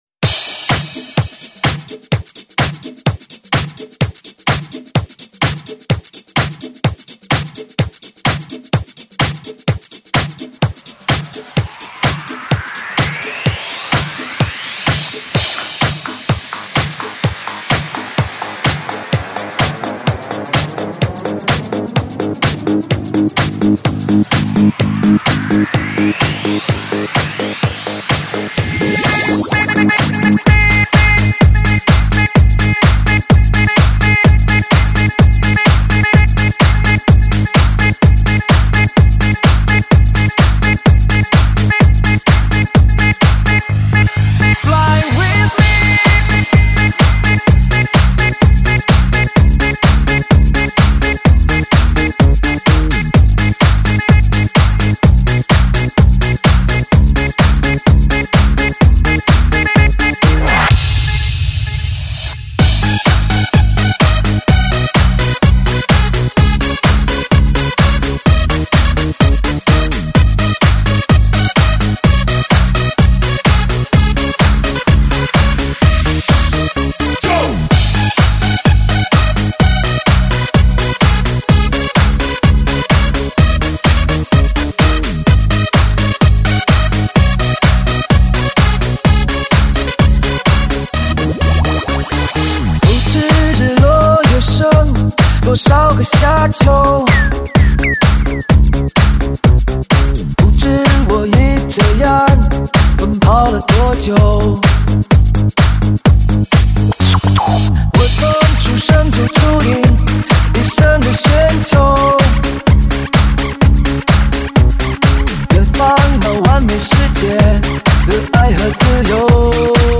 完美的旋律，激发引擎的律动(横扫DJ)排行超级劲曲，发烧电音舞曲大碟，震撼心灵的极品发烧，旋律激发引擎的共鸣。